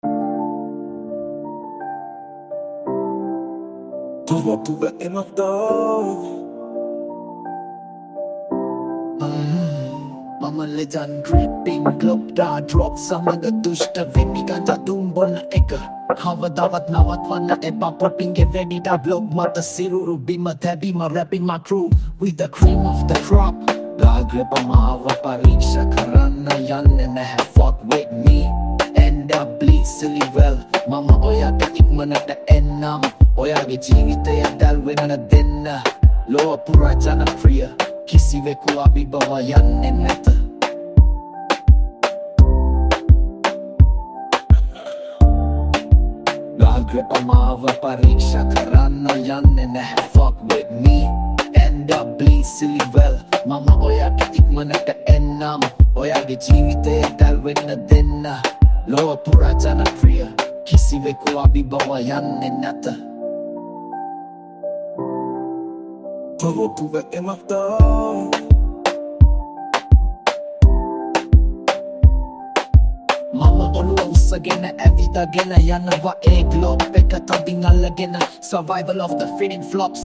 High quality Sri Lankan remix MP3 (1.8).
Rap